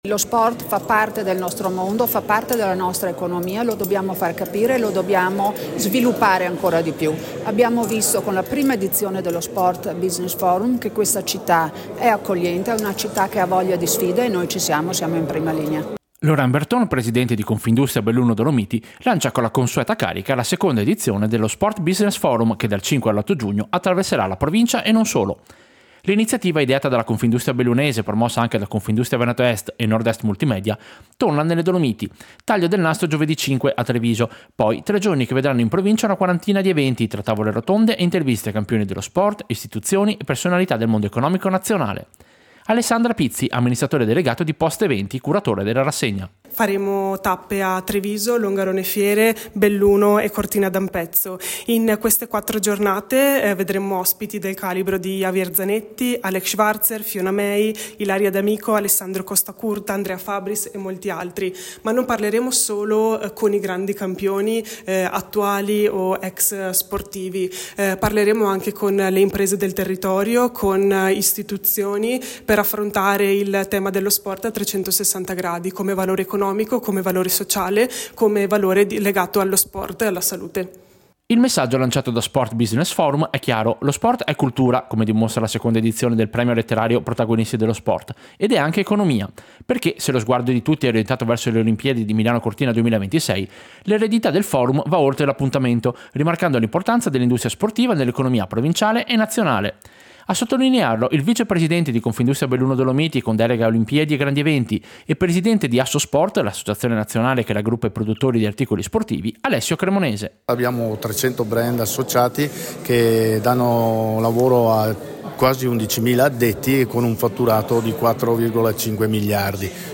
Servizio-Sport-Business-Forum-2025.mp3